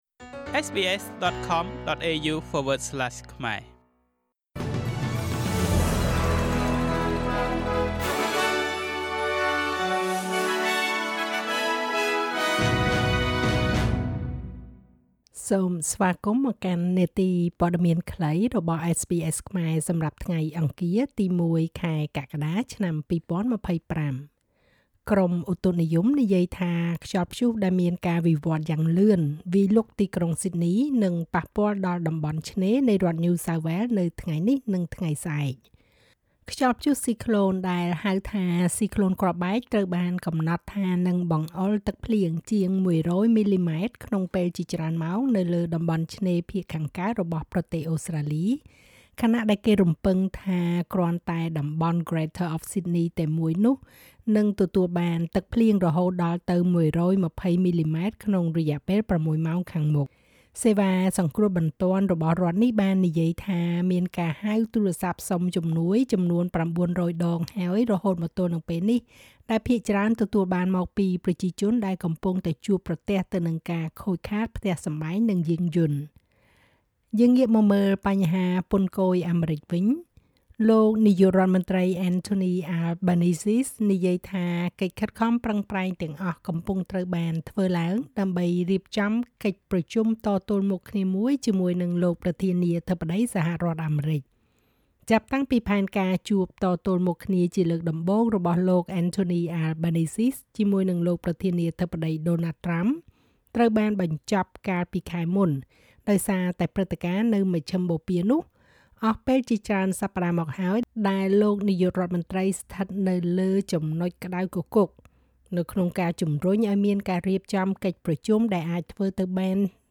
នាទីព័ត៌មានខ្លីរបស់SBSខ្មែរ សម្រាប់ថ្ងៃអង្គារ ទី១ ខែកក្កដា ឆ្នាំ២០២៥